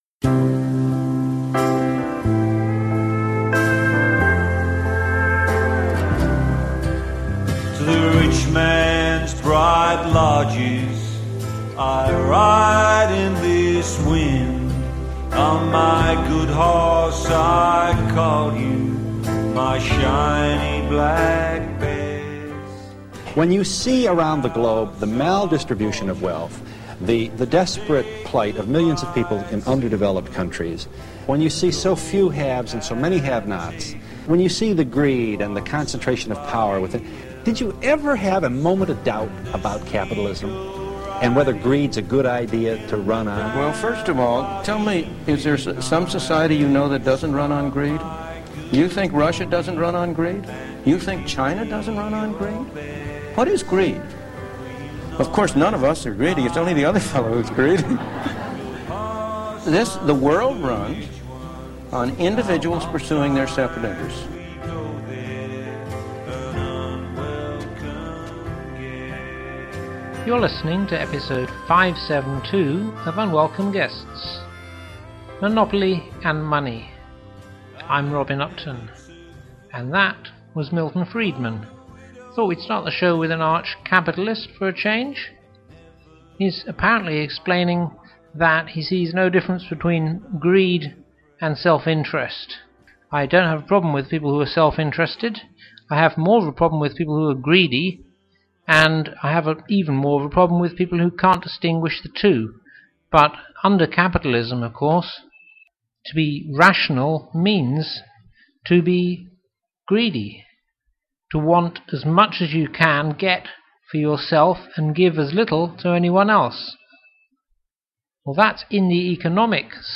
Are corporations really competing to bring better products to market more cheaply, or are they competing for control of government to grant them privileges? ug571-hour2mix.mp3 - mp3 27M We start the show this week with a few words from Milton Friedman!